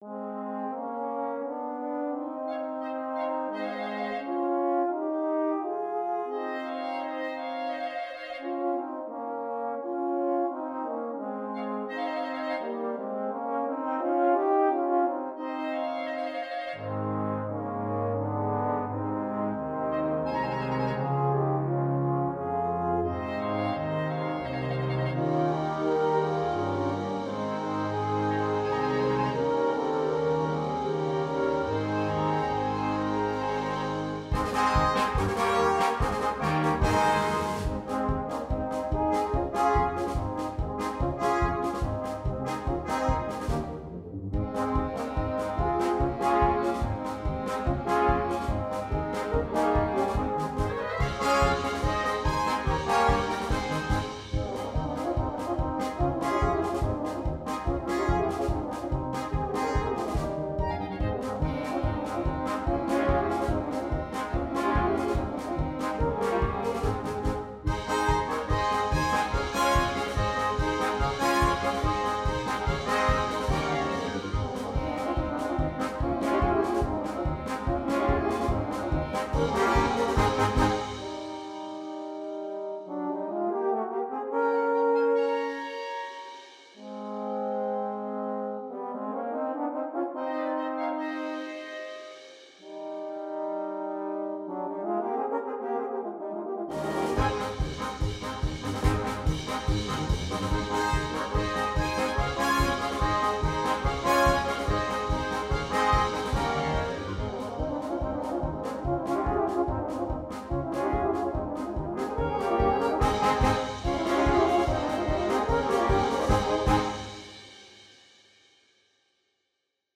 2. Blaskapelle
Duett
Tenorhorn / Bariton (Duett)
Unterhaltung